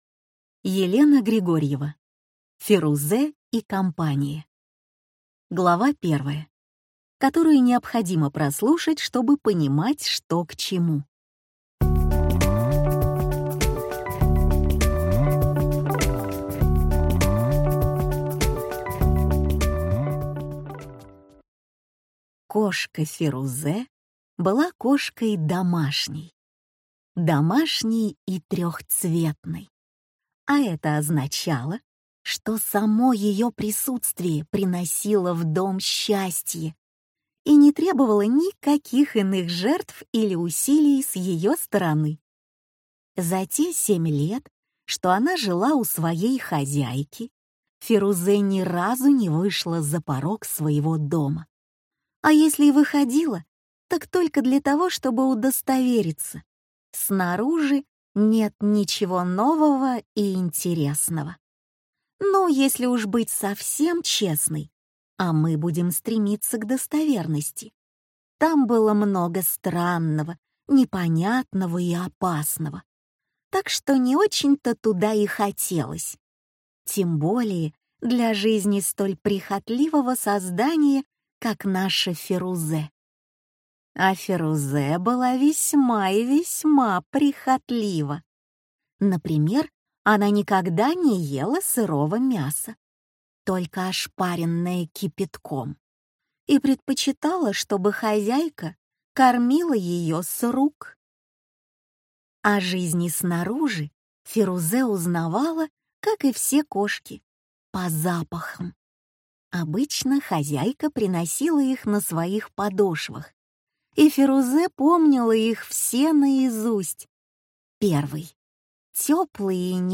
Аудиокнига Ферузе и компания | Библиотека аудиокниг